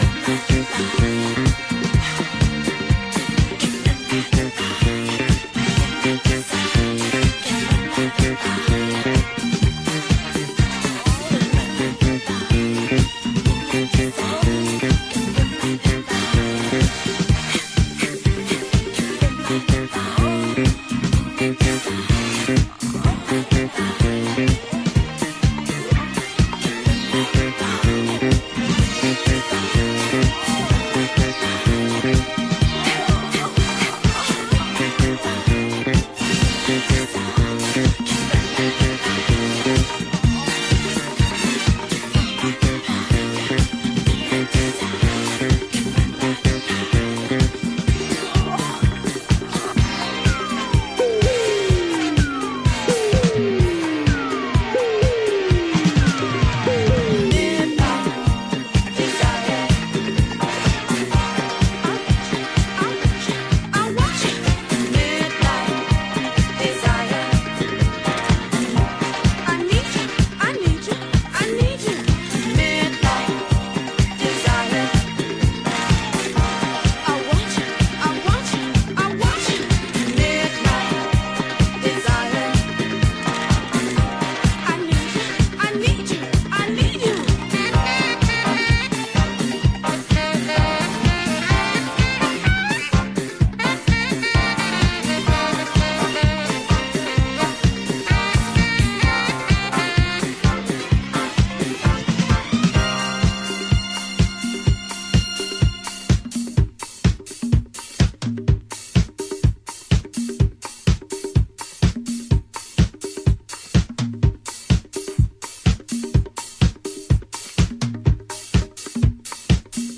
DISCO-BOOGIE-FUNK
horn section